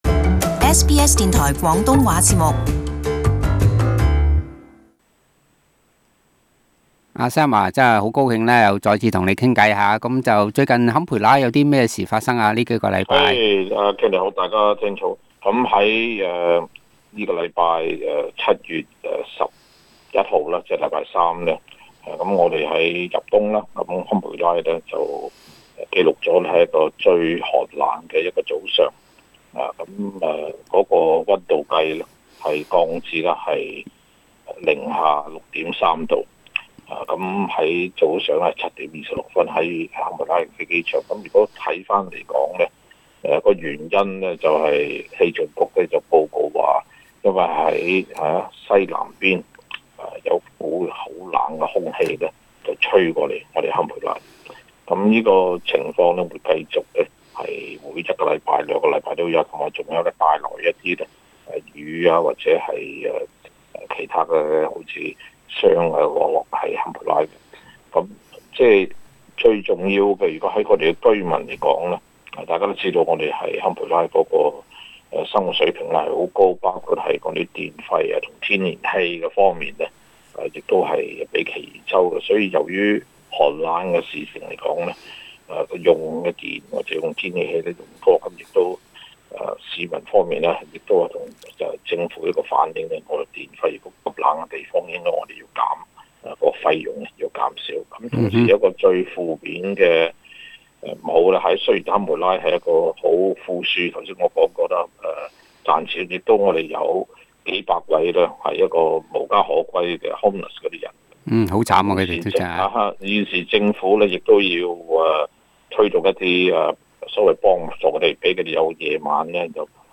(AAP) Source: AAP SBS廣東話節目 View Podcast Series Follow and Subscribe Apple Podcasts YouTube Spotify Download (13.6MB) Download the SBS Audio app Available on iOS and Android 首都坎培拉本周創下本年最低溫紀錄， 跌至零下6。